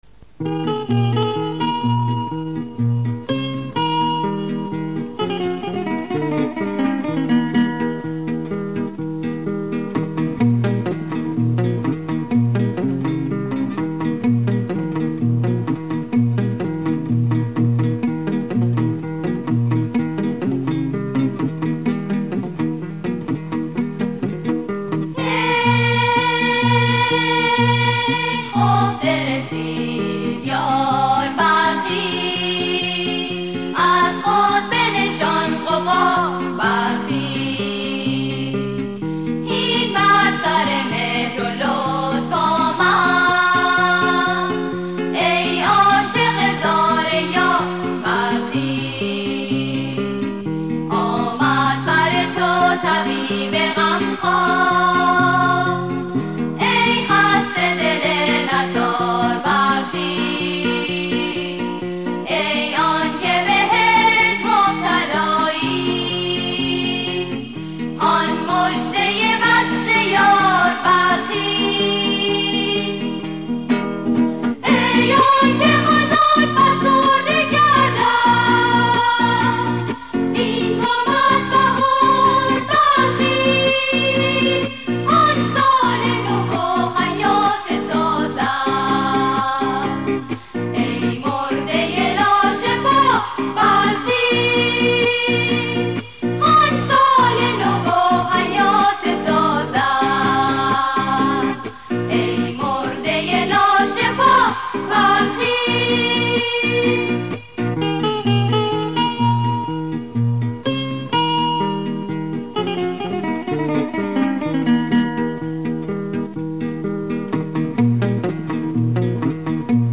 سرود - شماره 8 | تعالیم و عقاید آئین بهائی